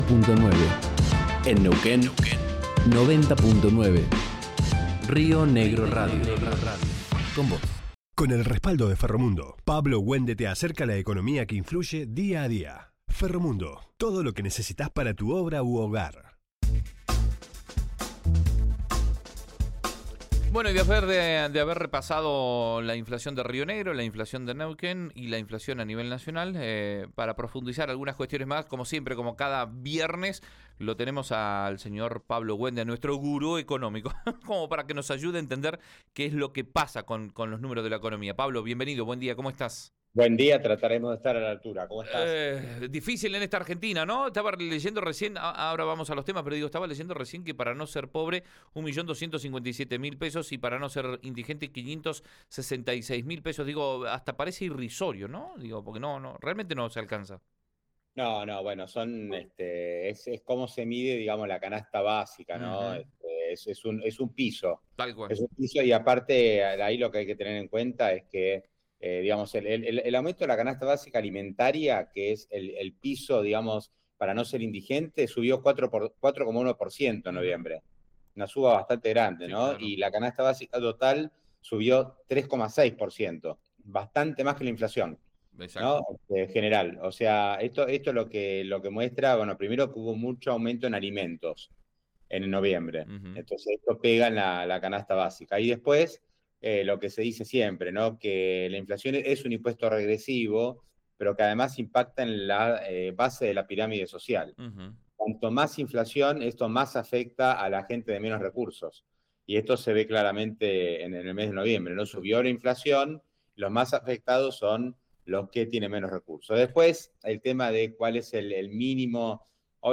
en su habitual columna en Río Negro Radio.